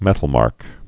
(mĕtl-märk)